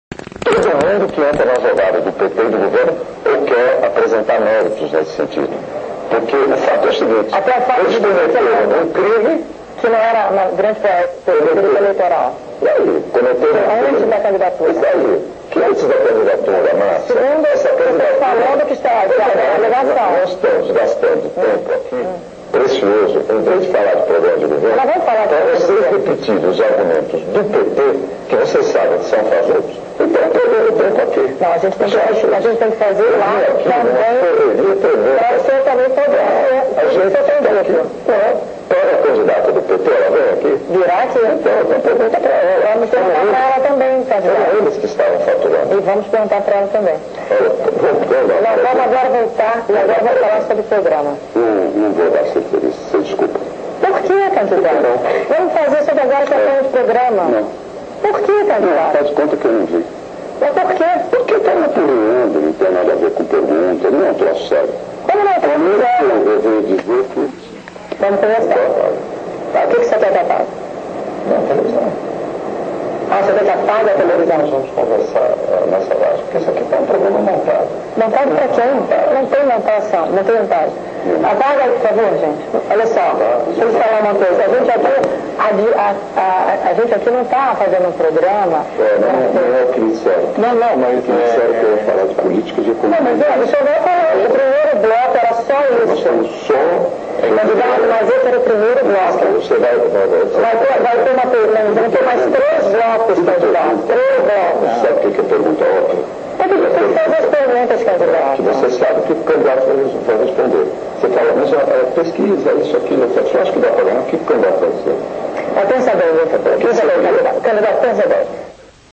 do momento em que o candidato José Serra discutiu com a entrevistadora Márcia Peltier, no intervalo de uma gravação do Programa Jogo do Poder, da CNT:
A discussão foi travada entre o primeiro e o segundo blocos do programa, ou seja, está gravada em alguma fita da emissora mas tudo indica que a CNT não vai colocar no ar (em geral esses momentos de intervalo são considerados off the records, mas se fosse a Dilma as imagens certamente seriam recuperadas e passariam no Jornal Nacional):
Serra-com-Marcia-Peltier.wma.mp3